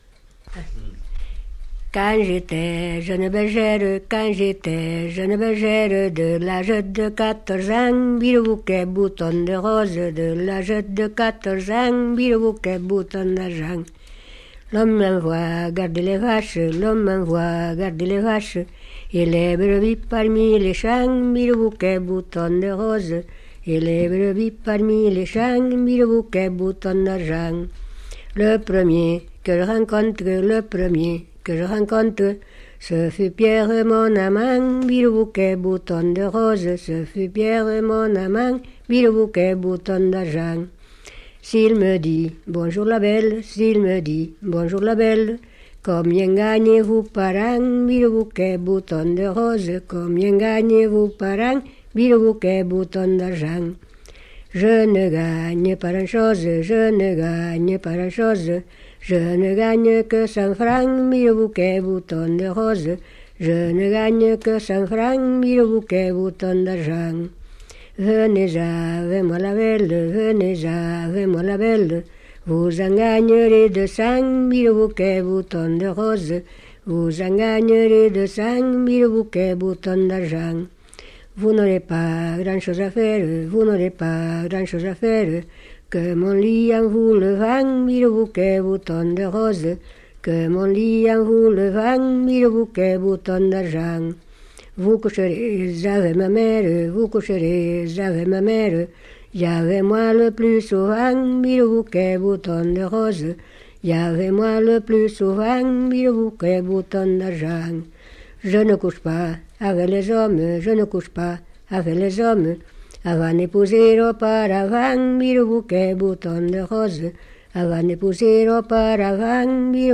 Lieu : Moncrabeau
Genre : chant
Effectif : 1
Type de voix : voix de femme
Production du son : chanté
Danse : rondeau